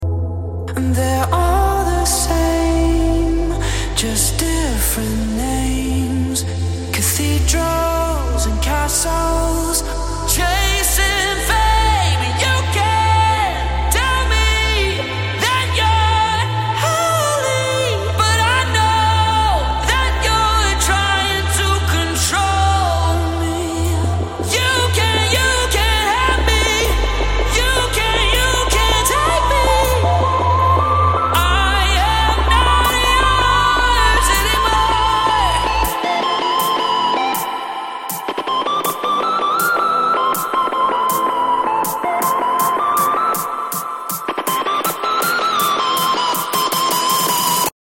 Euphoric Hardstyle